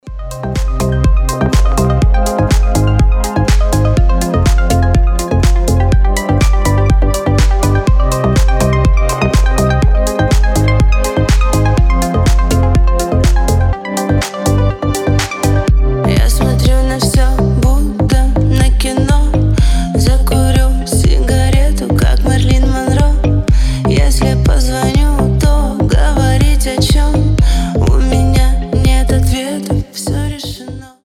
• Качество: 320, Stereo
мелодичные